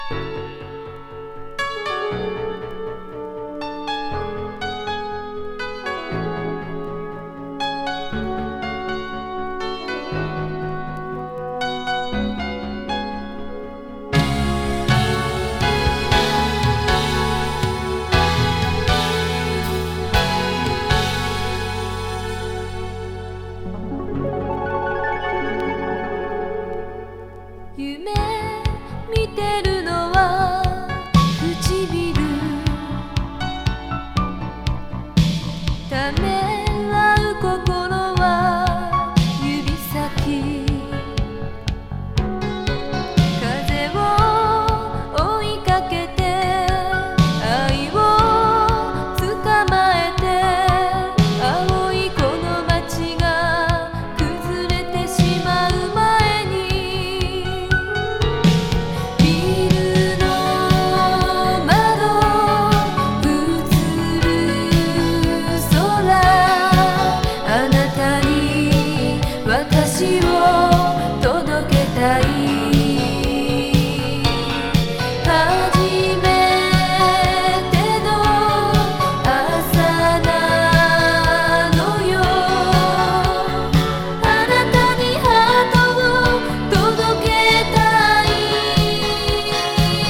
主題歌